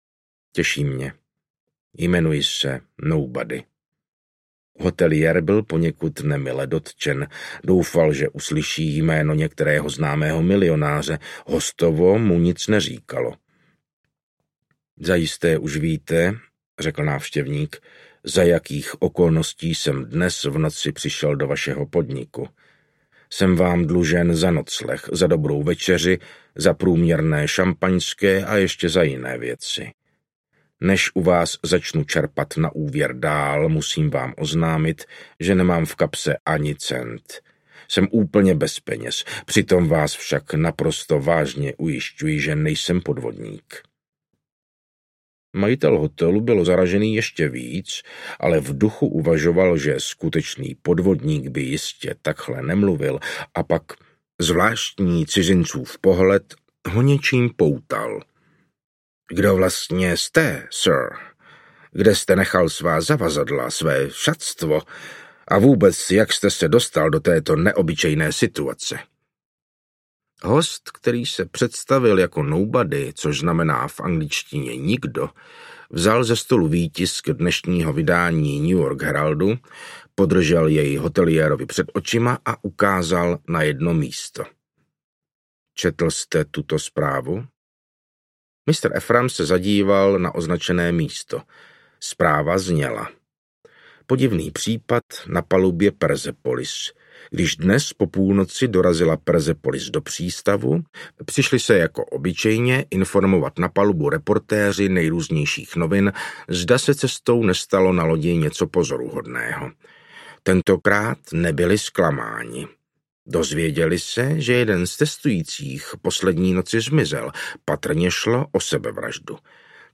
Ukázka z knihy
Čte Martin Finger.
Vyrobilo studio Soundguru.